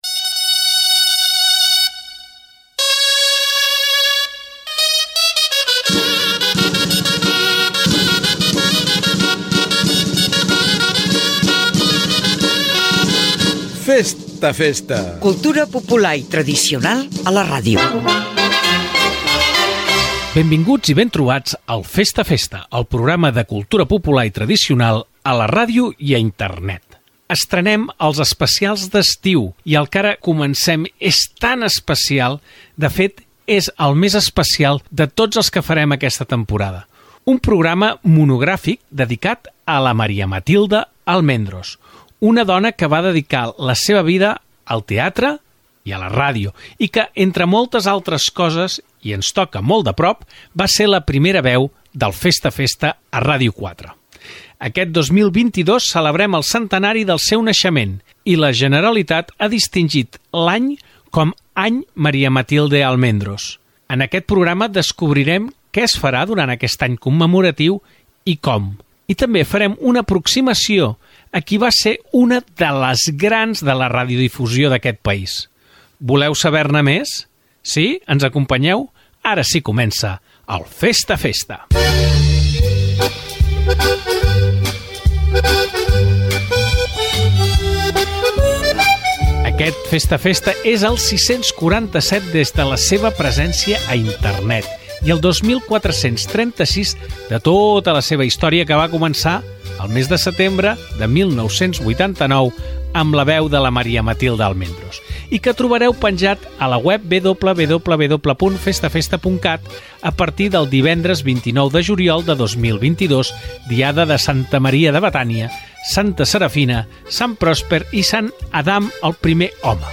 Careta, presentació de l'especial d'estiu dedicat a la locutora i actriu Maria Matilde Almendros amb motiu del centenari del seu naixement.
FM